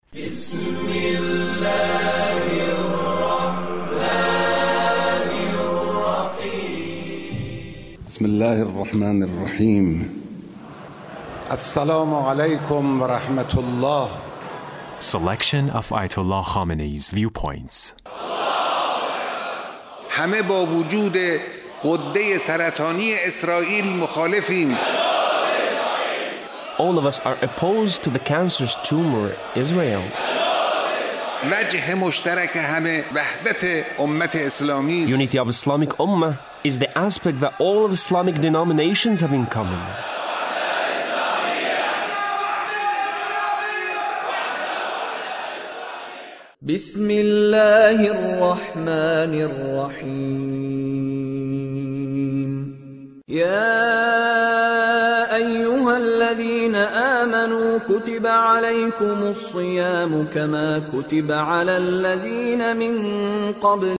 Leader's speech (73)